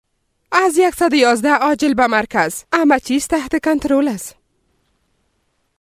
Dari Voice Sample